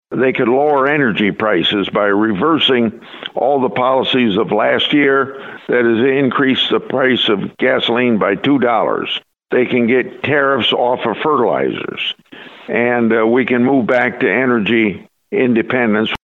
Hill Republicans, such as Iowa Senator Chuck Grassley, blame Biden’s energy policies.